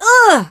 rosa_hit_05.ogg